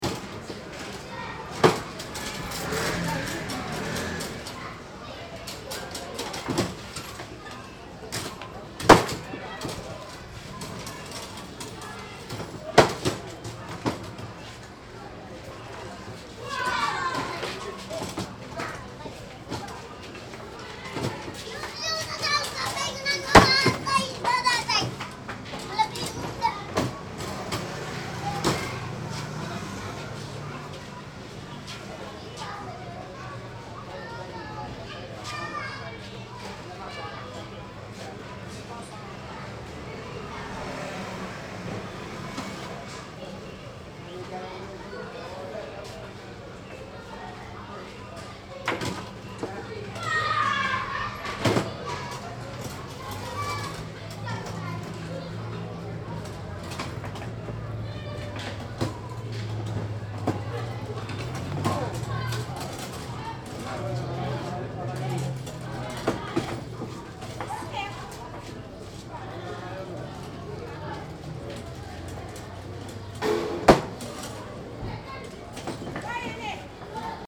A la médina, quand on sent que la fatigue et la température montent, on peut faire une pause chez la vendeuse de glaces. J'entends par là qu'on peut s'asseoir sur le trottoir à côté de sa chaise en plastique, protégé du soleil par le haut-vent d'une boutique, et lui demander de sortir un délicieux sachet de la glacière posée à ses pieds. Ici il y a deux parfums : le bouille, jus de fruit du baobab qui est onctueux et sucré, ou le bissap, infusion d'hibiscus au goût de cassis.